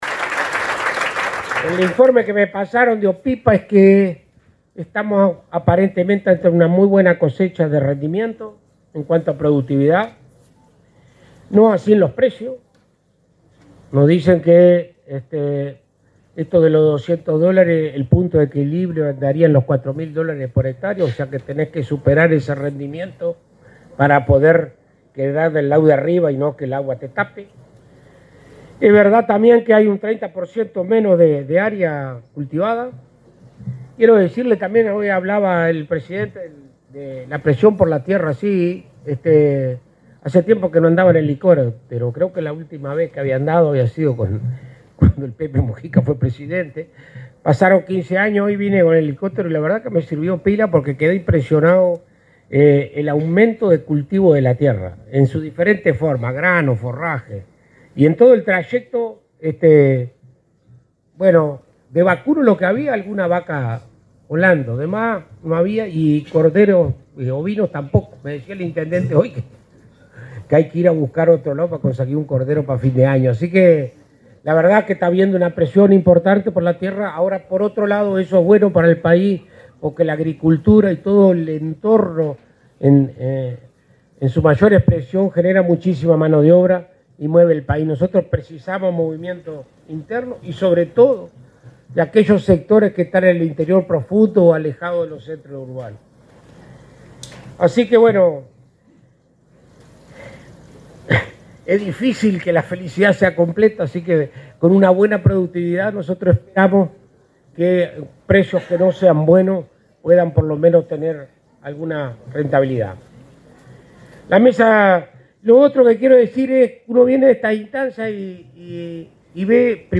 Palabras del ministro de Ganadería, Agricultura y Pesca, Alfredo Fratti 14/11/2025 Compartir Facebook X Copiar enlace WhatsApp LinkedIn El ministro de Ganadería, Agricultura y Pesca, Alfredo Fratti, se expresó durante la inauguración de la cosecha de cebada, realizada en Ombúes de Lavalle, departamento de Colonia.